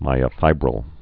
(mīə-fībrəl, -fĭbrəl)